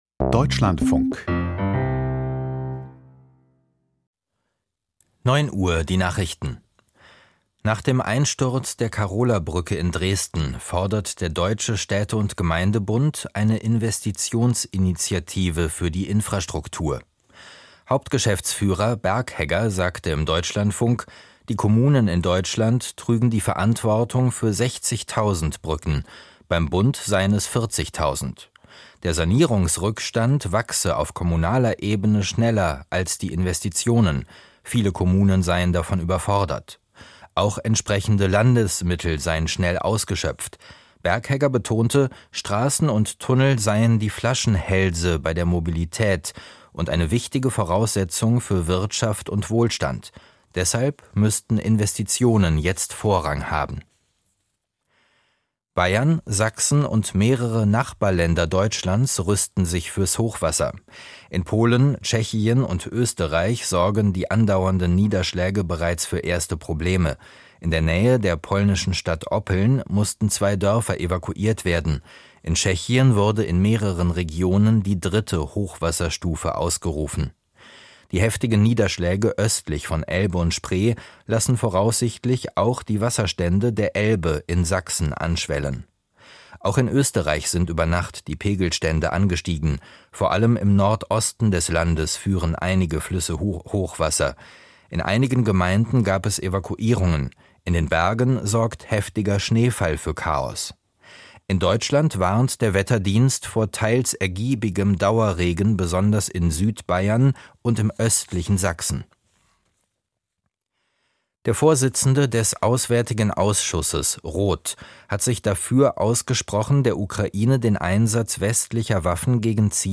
Strategische Bedeutung des Angriffs? - Interview